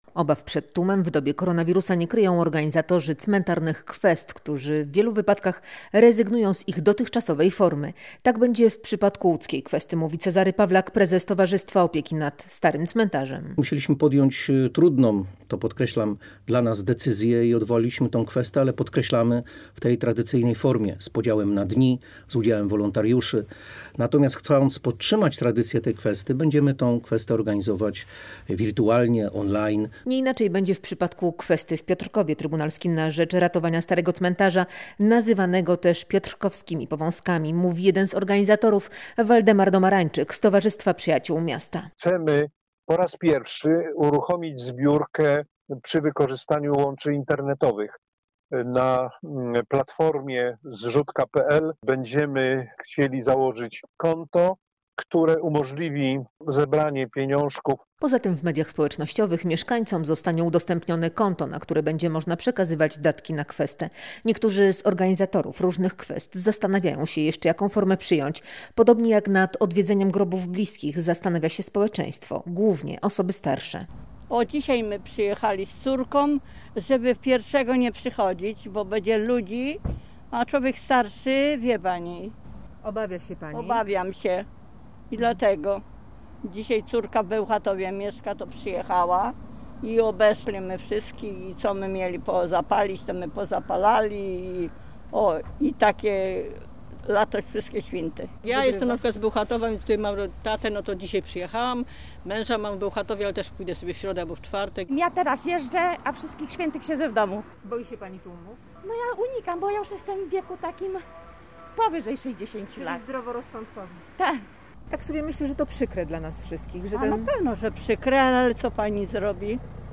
– Ja teraz jeżdżę, a na Wszystkich Świętych siedzę w domu – mówią odwiedzający cmentarz.
Ludzie jest mniej i mniej kupują zniczy i kwiatów – mówią sprzedawcy.